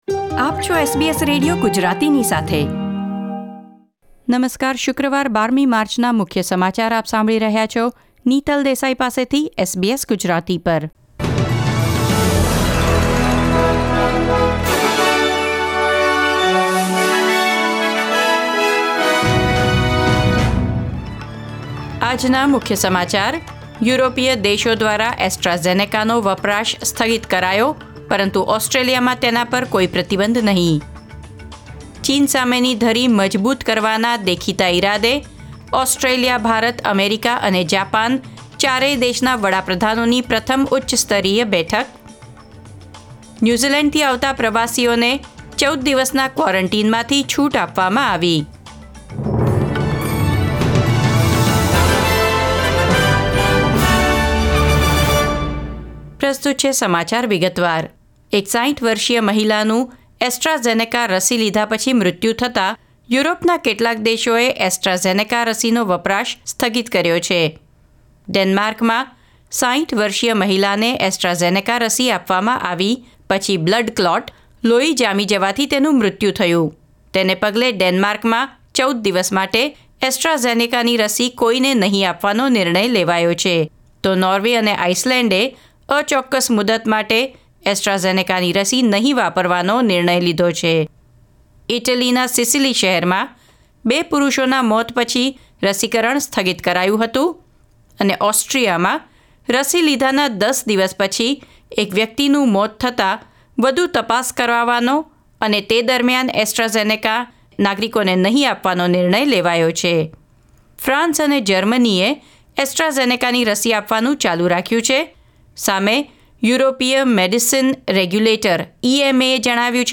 SBS Gujarati News Bulletin 12 March 2021